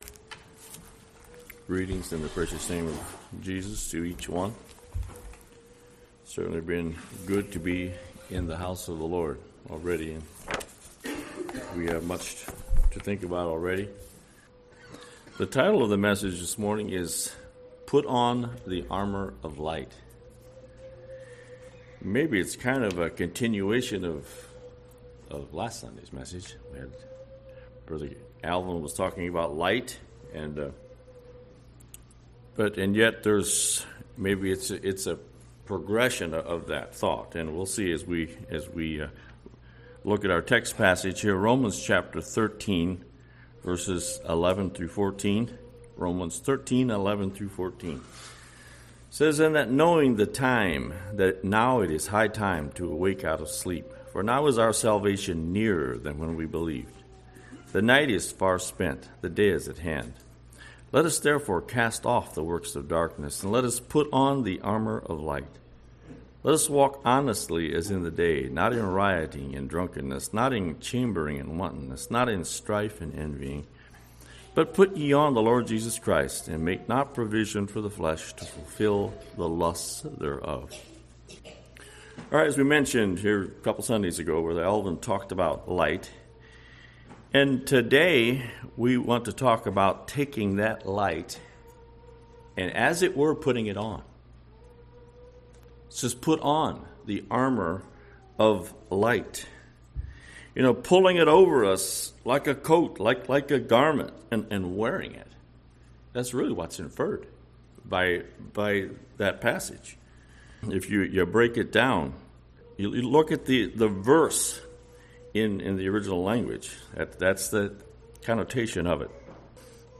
This message will help you in your persuit of God .Listen to the audio sermon